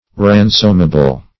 Ransomable \Ran"som*a*ble\ (-[.a]*b'l), a.
ransomable.mp3